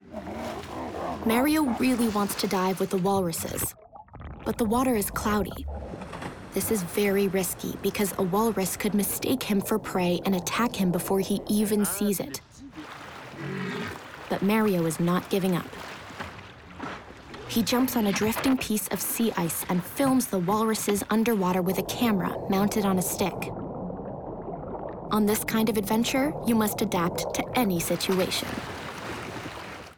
Narration (Discover the Artic) - EN